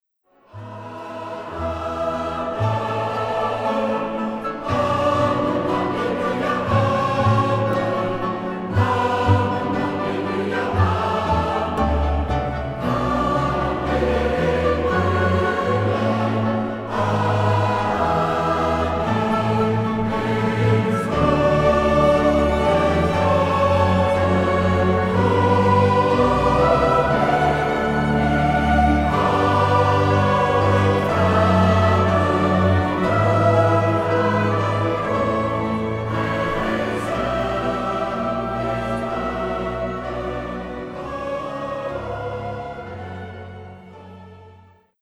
Oratorium over het leven van Mozes